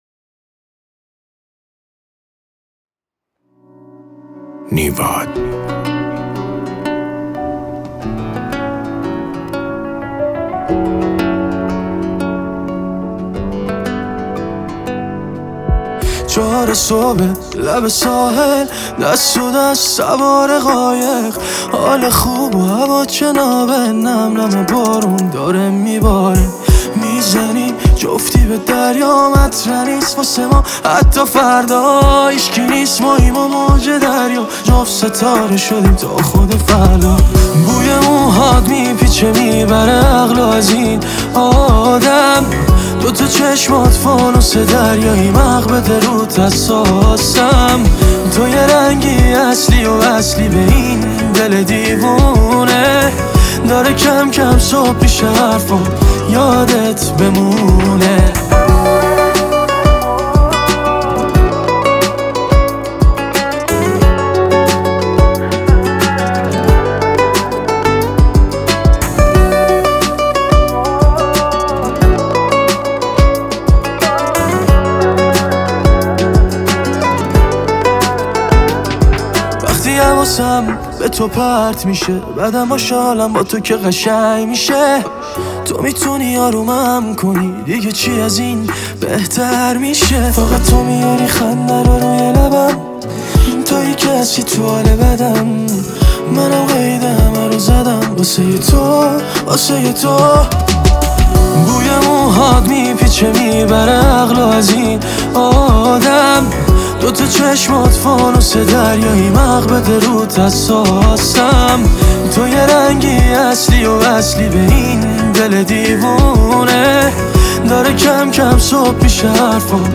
خواننده‌ی جدید سبک پاپ
عاشقانه